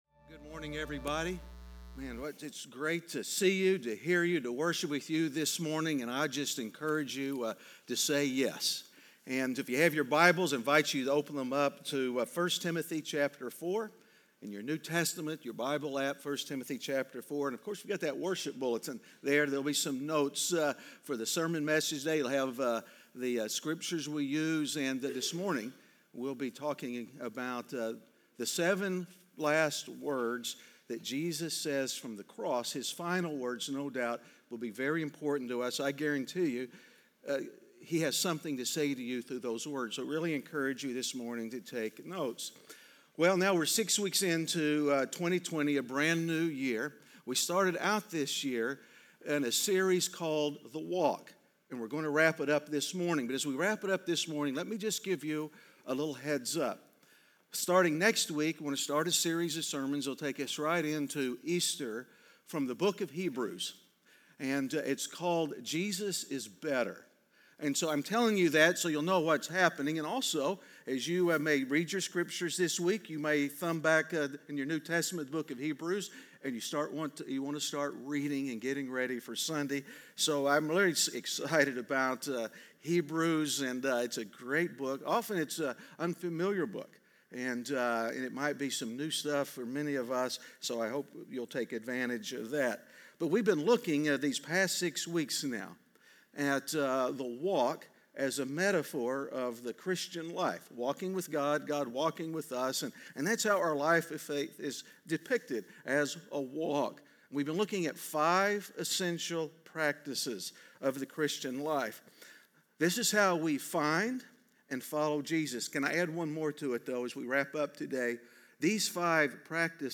A message from the series "The Walk." Generosity is an indication of the heart. What are the ways we can be more generous?